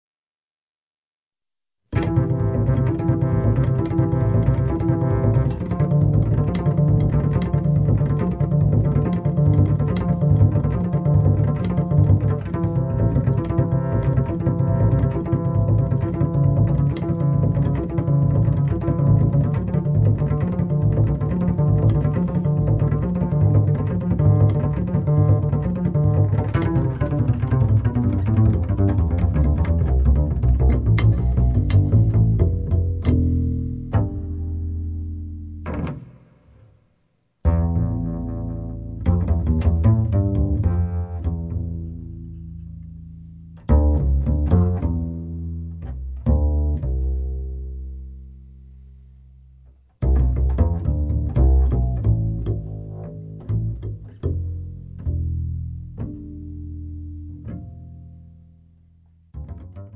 Piano
Palmas & Cajon
Acoustic Bass/Electric Bass